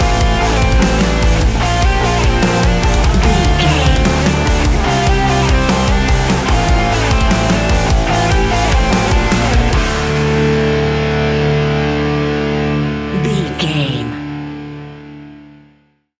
Ionian/Major
D♭
hard rock
instrumentals